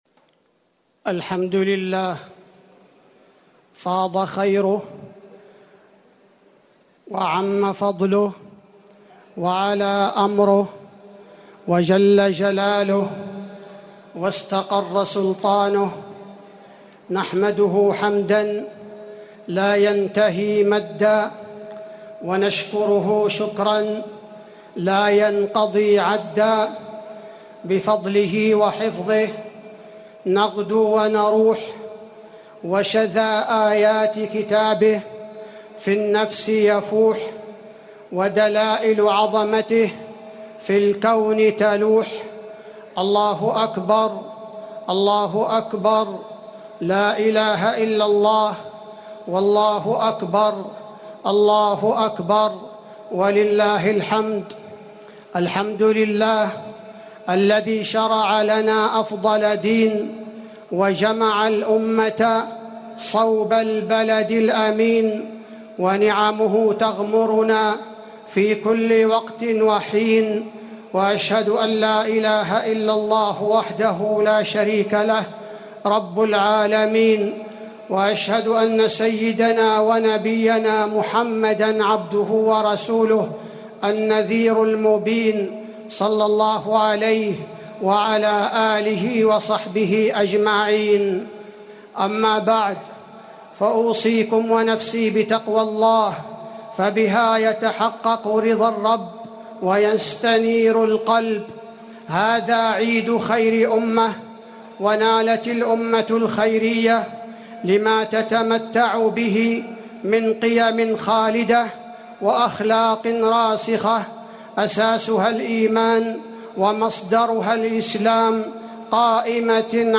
خطبة عيد الأضحى - المدينة - الشيخ عبدالباري الثبيتي
تاريخ النشر ١٠ ذو الحجة ١٤٤٠ هـ المكان: المسجد النبوي الشيخ: فضيلة الشيخ عبدالباري الثبيتي فضيلة الشيخ عبدالباري الثبيتي خطبة عيد الأضحى - المدينة - الشيخ عبدالباري الثبيتي